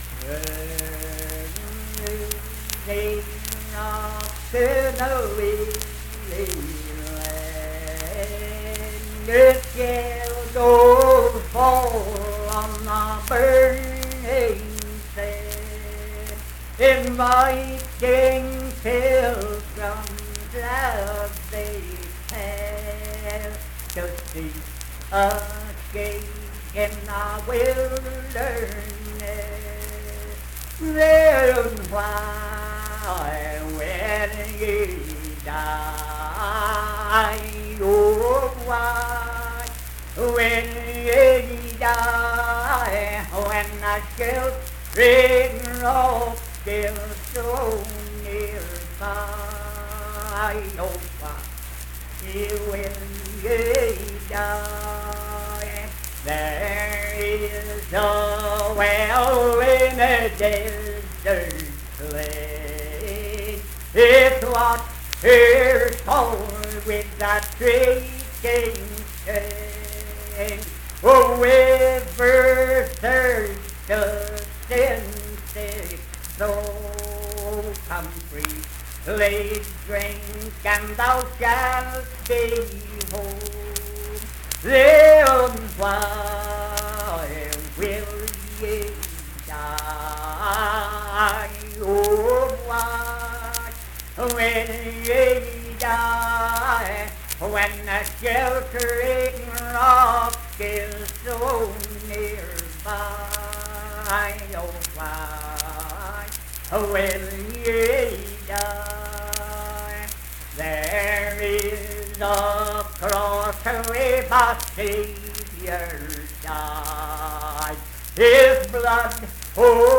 Unaccompanied vocal music performance
Verse-refrain 3(4) & R(5).
Hymns and Spiritual Music
Voice (sung)